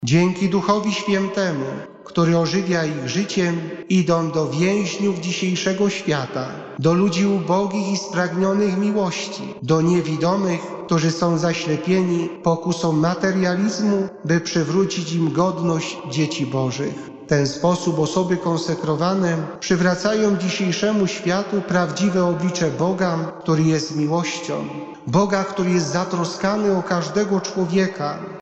W stołecznej bazylice św. Krzyża modlono się w intencji osób poświęconych Bogu.
W homilii bp Kiciński podkreślił wartość życia konsekrowanego które jak zaznaczył – ukazuje nam obecność Boga pośród swojego ludu.